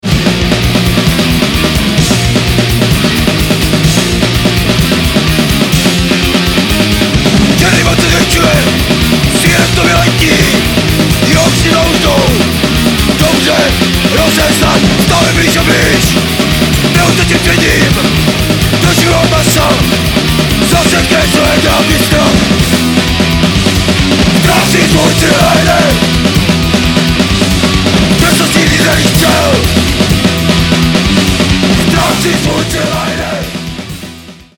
Nahrávací studio v Lipově audio / digital
HC/PUNK